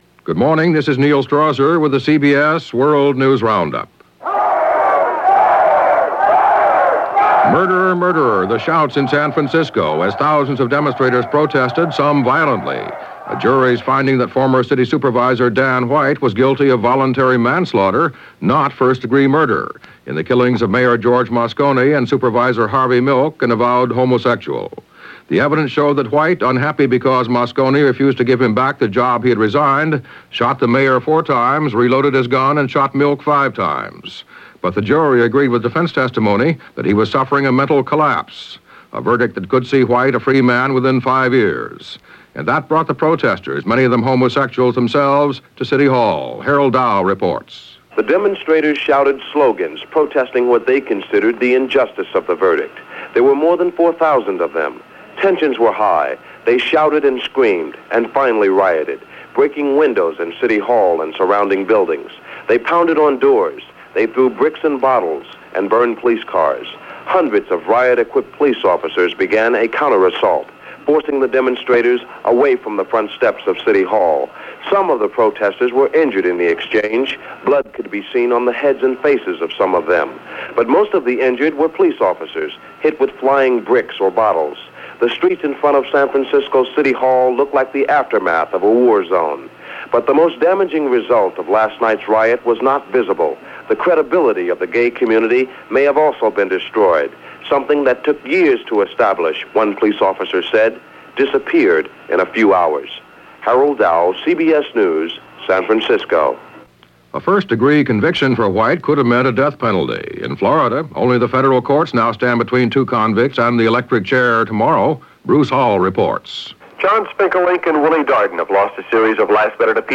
– May 22, 1979 – CBS World News Roundup – Gordon Skene Sound Collection –
And that’s just a little of what happened this May 22, 1979, as reported on The CBS World News Roundup.